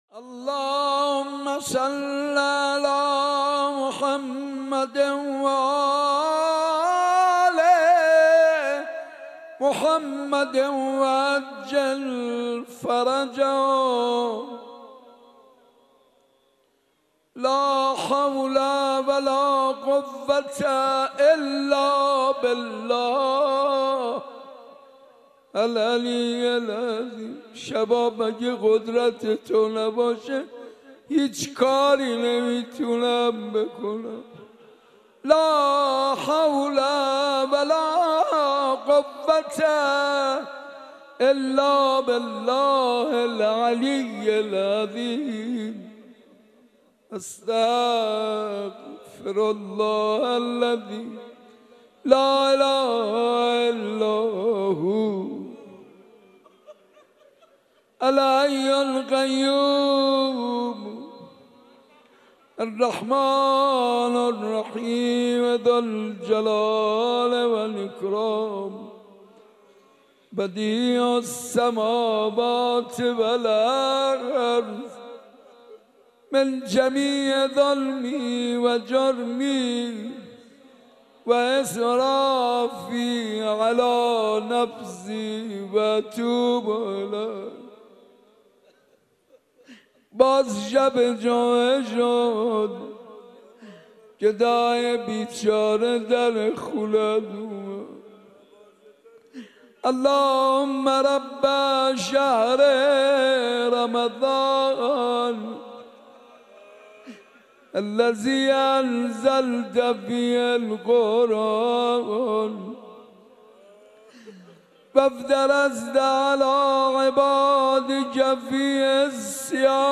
حاج منصور-شب نهم-مسجد ارک